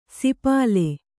♪ sipāle